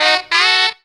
2 NOTE FIFF2.wav